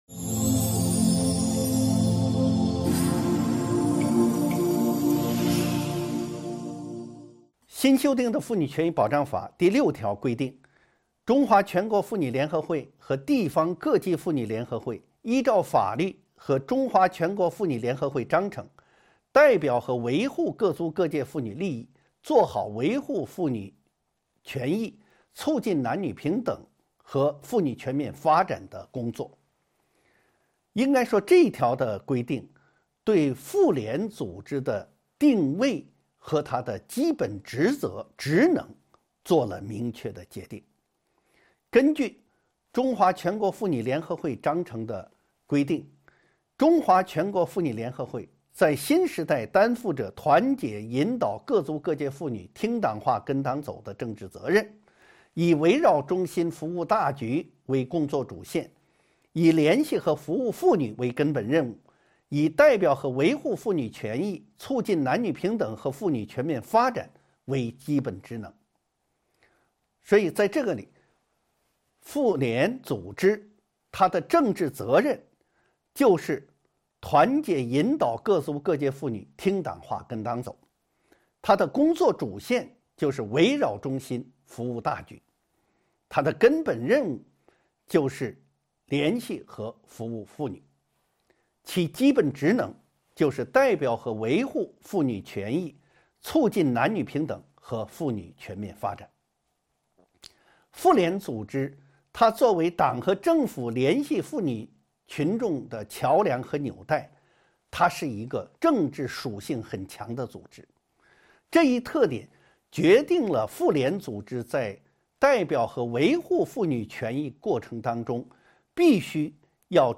音频微课：《中华人民共和国妇女权益保障法》9.妇联组织的定位与法定职责